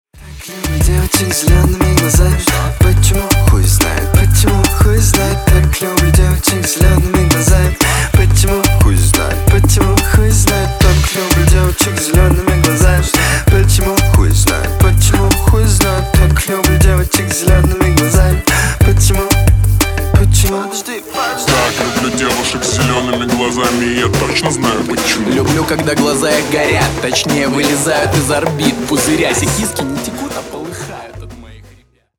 Поп Музыка
весёлые